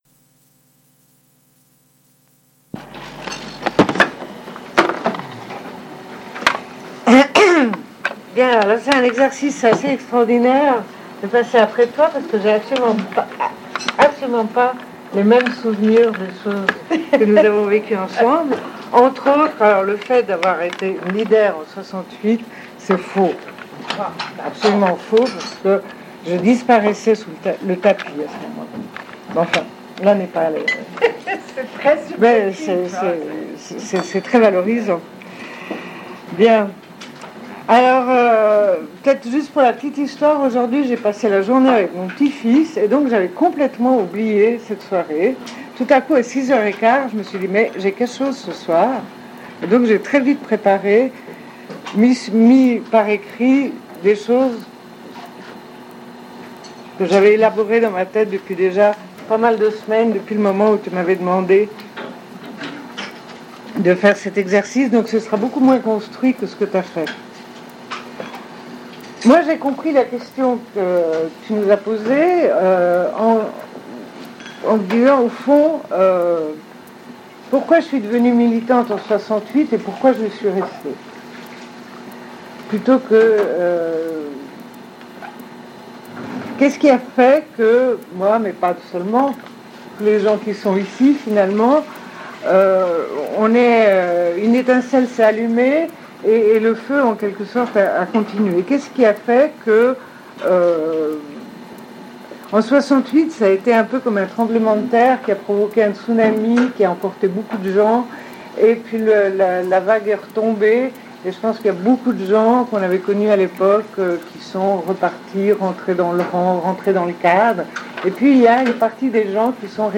Une cassette audio
Témoignage